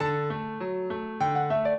piano
minuet0-4.wav